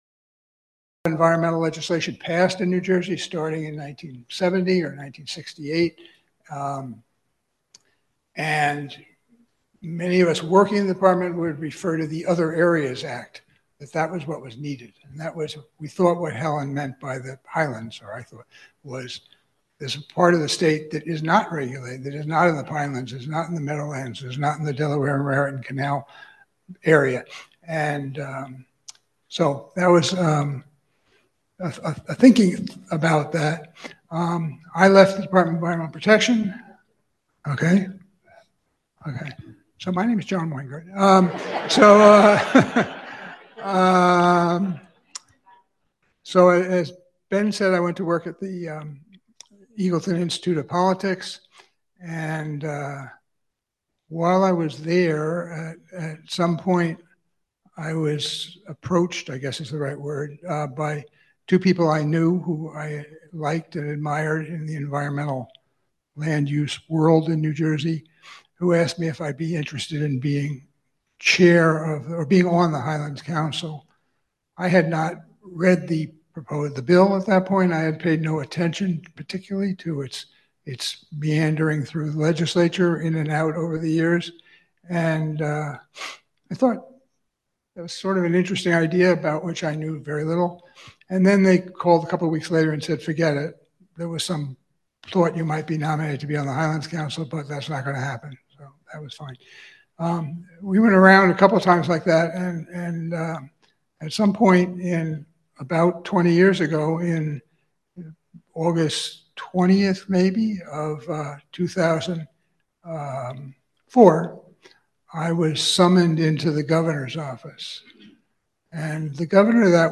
To recognize this significant milestone, the Highlands Council invited municipal, county, and nonprofit leaders to attend a 20th Anniversary Summit to review progress on implementation of the Act as well as challenges for the future.
Panel_AudioONLY.mp3